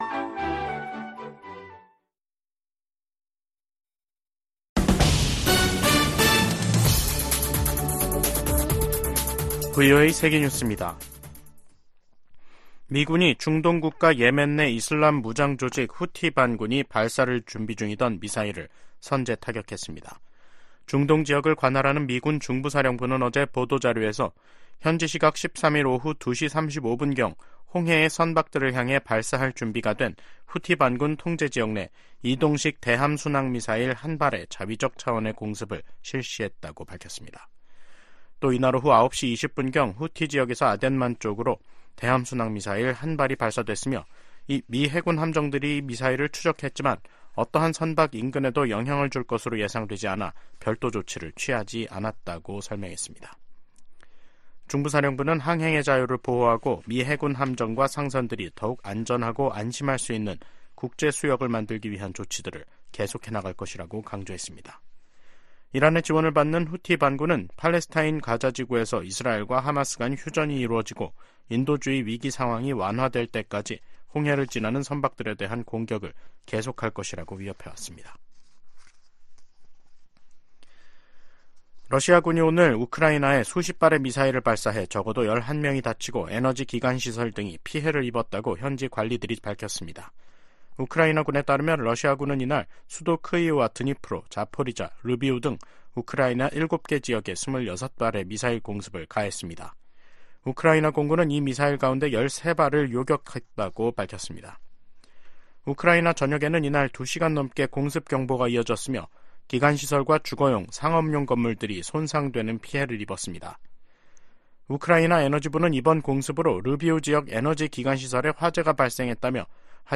VOA 한국어 간판 뉴스 프로그램 '뉴스 투데이', 2024년 2월 15일 2부 방송입니다. 북한이 신형 지상 대 해상 미사일 시험발사를 실시했다고 관영 매체가 보도했습니다. 미 국무부 고위 당국자가 북한-러시아 밀착에 깊은 우려를 나타내며 다자 협력의 필요성을 강조했습니다.